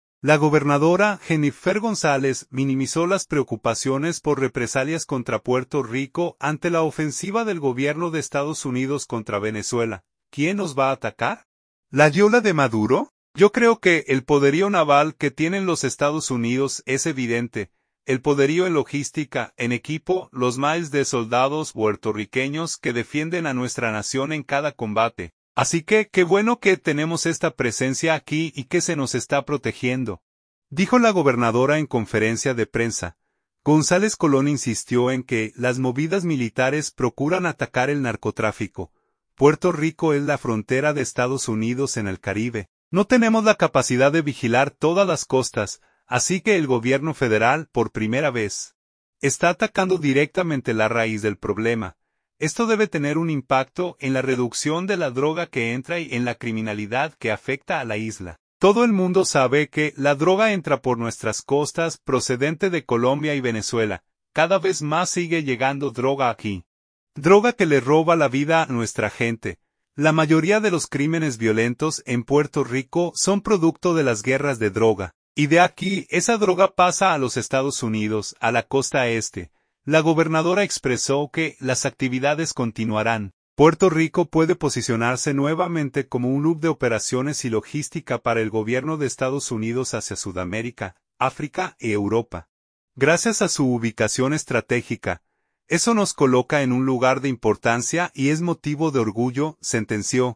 Así que qué bueno que tenemos esta presencia aquí y que se nos está protegiendo”, dijo la gobernadora en conferencia de prensa.